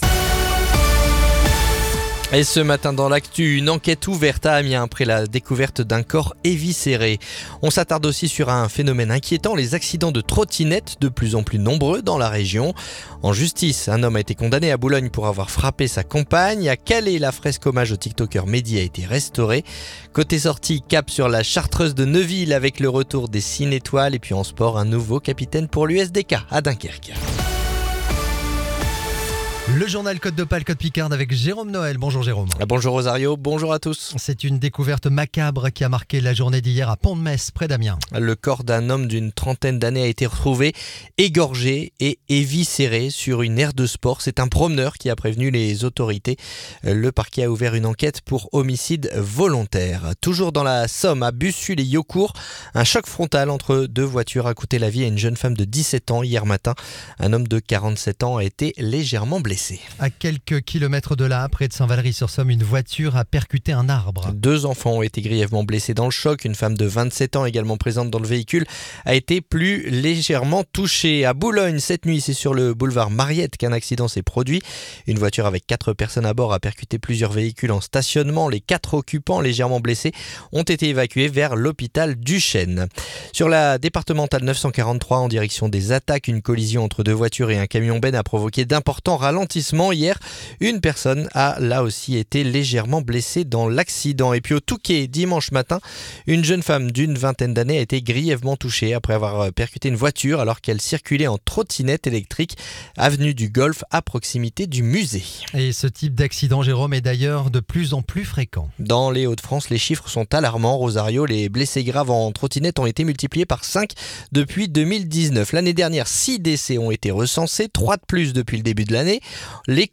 Le journal du mardi 5 août